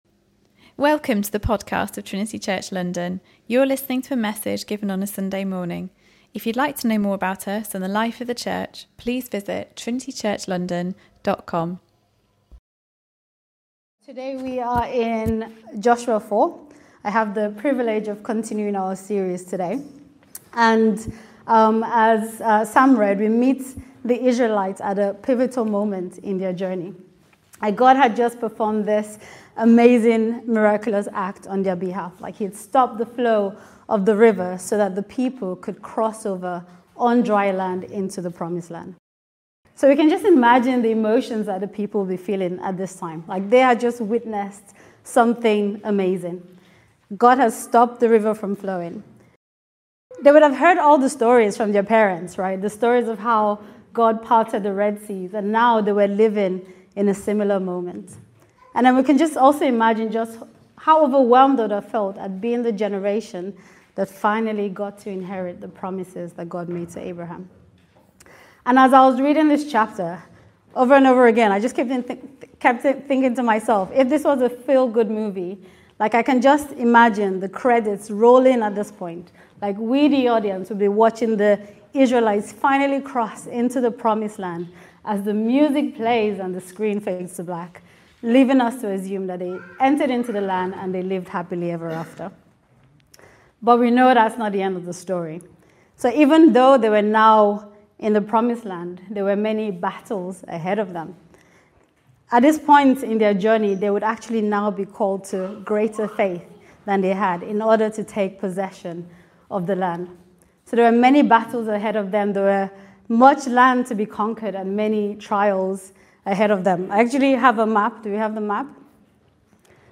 In this sermon, we explore the powerful moment when the Israelites crossed the River Jordan into the Promised Land.